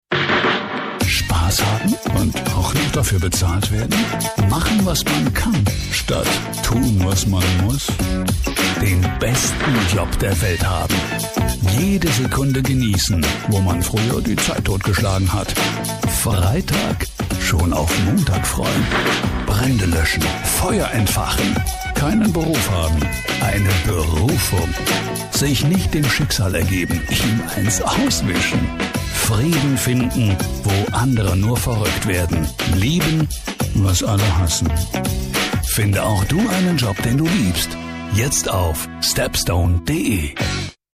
Werbung (TV, Radiospots)
Werbung. Spot “Stepstone”